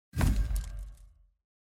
reel-stop-1.mp3